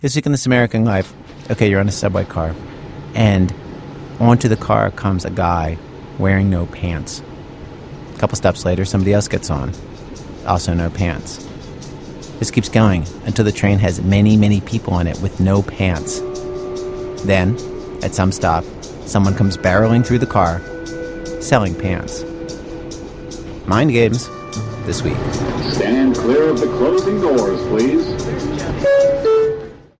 Prologue: Host Ira Glass interviews Lori Gottlieb about the time she sent a letter to a writer in a magazine, a letter packed with white lies.